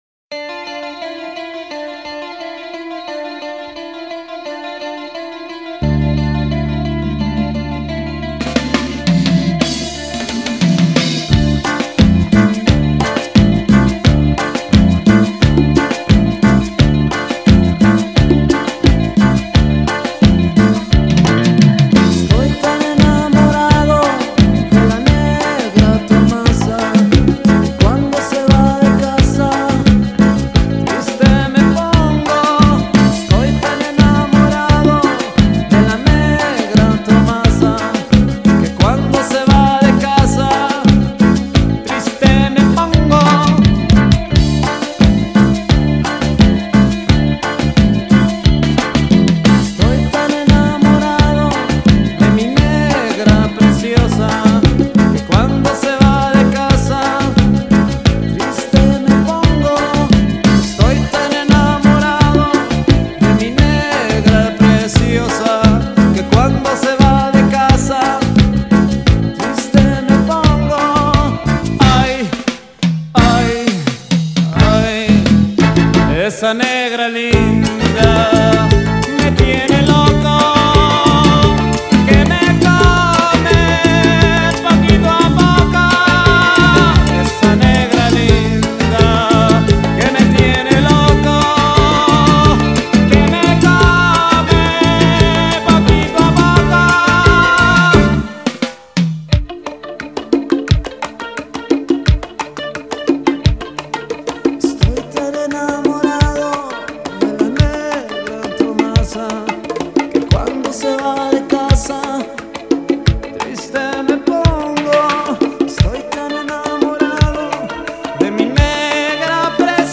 Esta etapa sería de experimentos y más relajo en general.
un tambor bongo